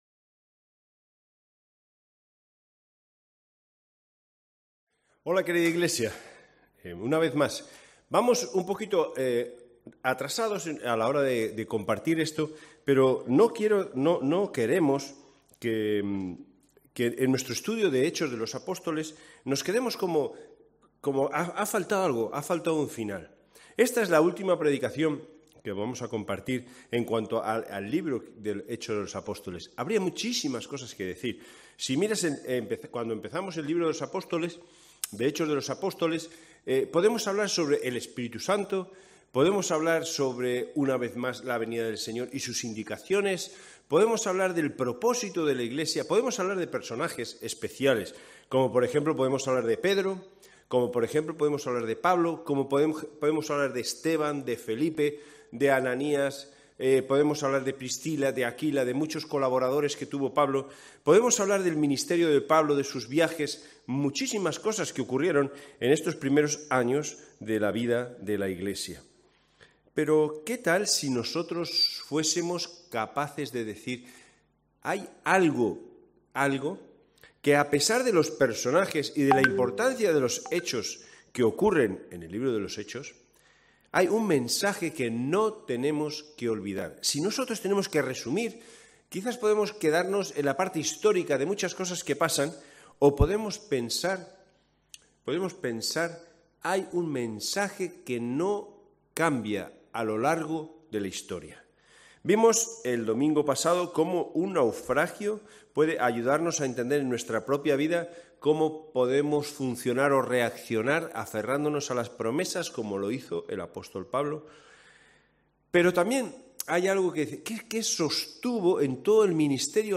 Hechos 26:28-30 Series: Los Hechos del Espíritu Santo Etiquetado como defensa , evangelio , mensaje , poder , predicación , testimonio